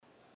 تاريخ النشر ٩ جمادى الأولى ١٤٤٠ هـ المكان: المسجد الحرام الشيخ